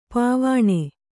♪ pāvāṇe